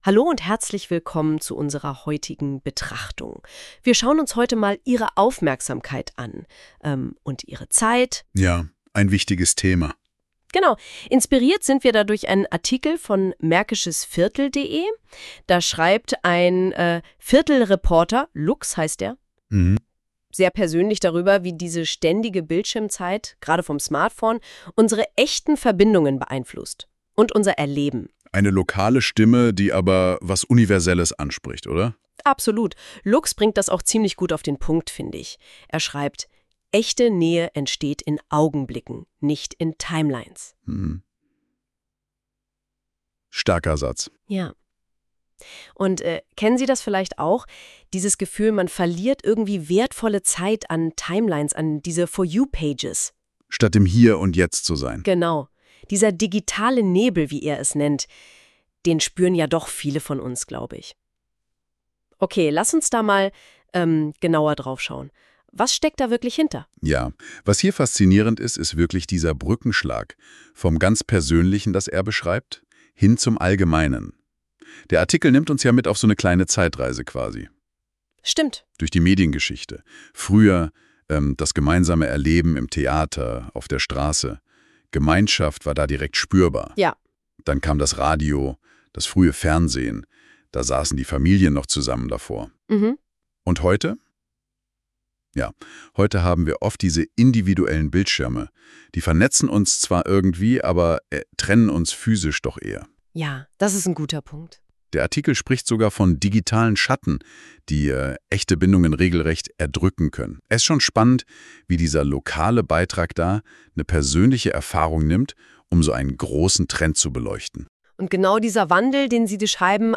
Gelesen wird der Text von einer realistisch klingenden KI-Stimme, die distanziert erzählt und uns doch ganz nah kommt.